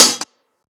Live Hat - 90210.wav